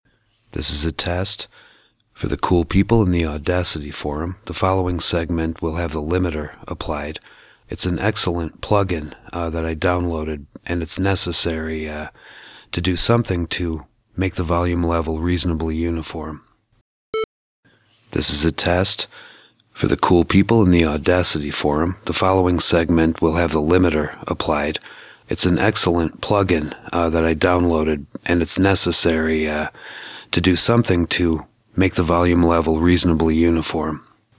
So, IMO, De-Essing should be applied after any limiter/compressor/EQ.
Desibilator, LevelSpeech2, RMS Normalization = Loudness Normalization